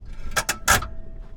Polaris, Ranger, t9, Var SFX, Parking Brake, Onboard, Left, CMC6.ogg